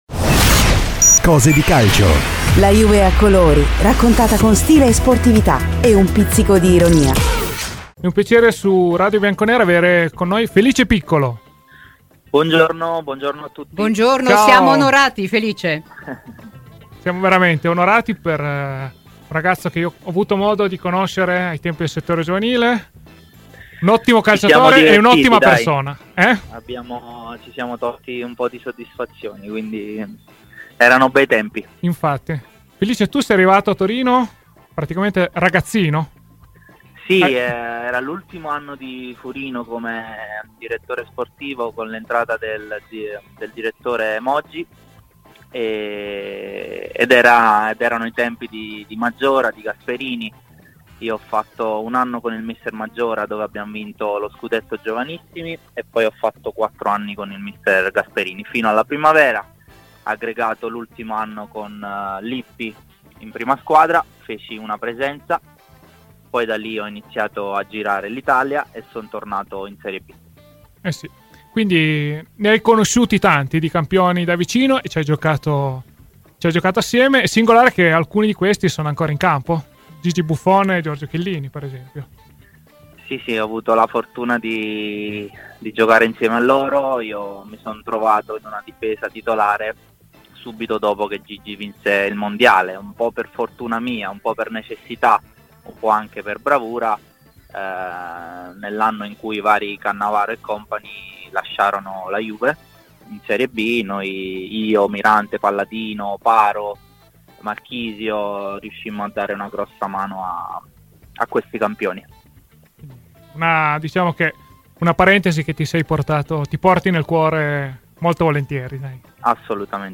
Ai microfoni di Radio Bianconera, nel corso di ‘Cose di Calcio’